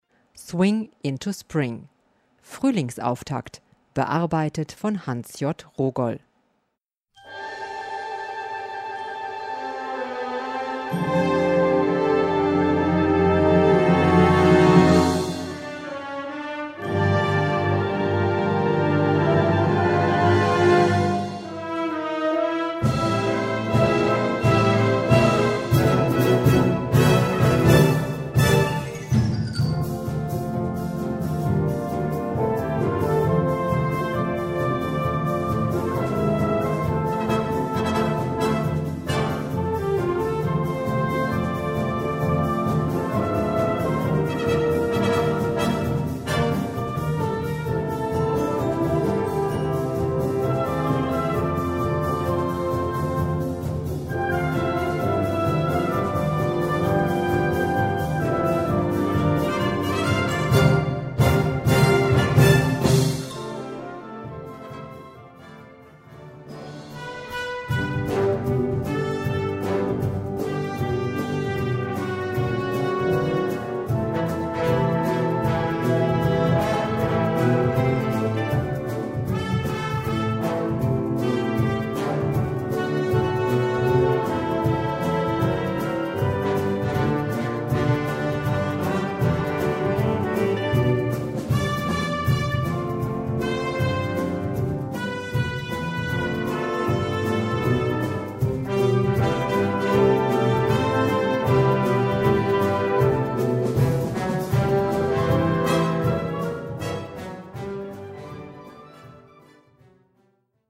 Gattung: Medley
Besetzung: Blasorchester
swingendes Medley